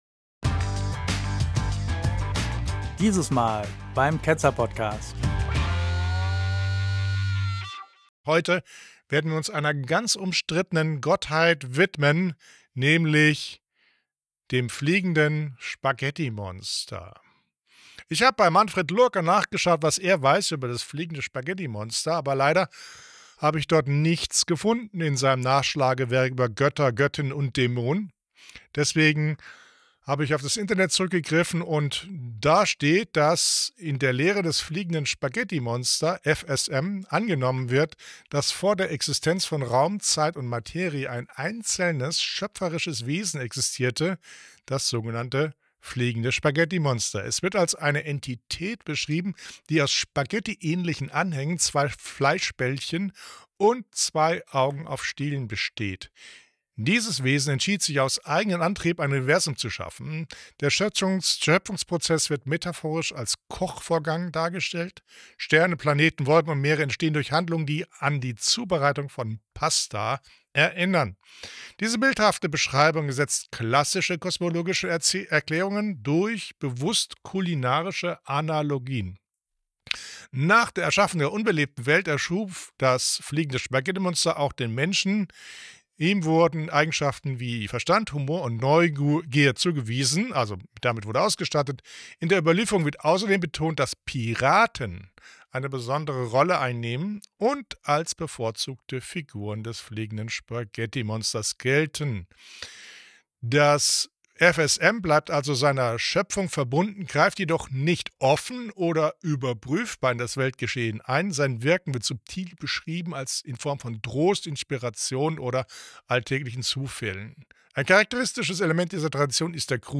Eine der wichtigsten Gottheiten der Neuzeit ist zweifellos das Fliegende Spaghettimonster: Wir skizzieren die Lehre vom kulinarisch gefassten Schöpfungsakt, bei dem Sterne und Menschen wie Pasta „zubereitet“ werden, mit Piraten als bevorzugten Figuren. Wir ordnen den Pastafarismus zwar als bewusste Parodie ein, sprechen aber auch darüber, dass manche Anhänger dies bestreiten. Als investigatives journalistisches Magazin unterhalten wir uns auch mit Aussteigern, die einen erschütternden Einblick geben in die perfide Zweideutigkeit mancher Texte und Aussagen.